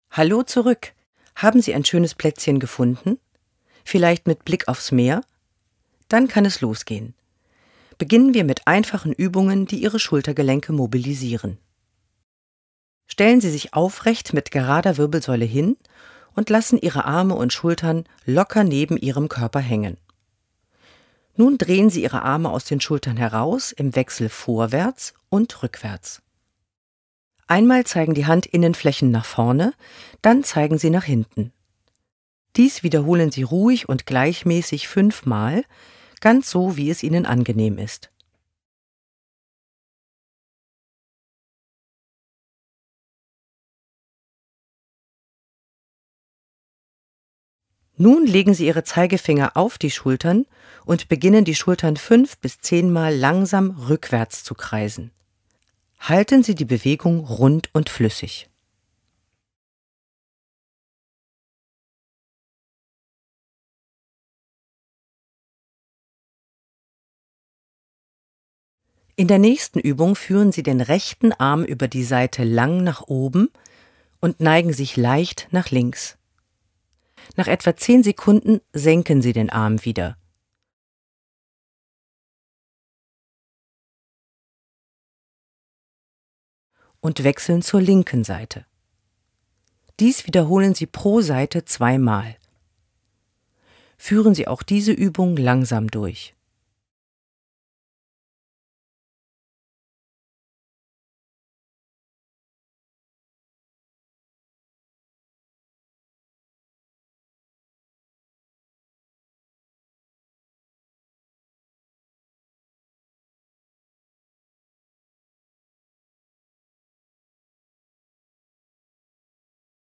Audioguide
Verantwortlich für die Tonaufnahmen: Tonstudio an der Hochschule Stralsund.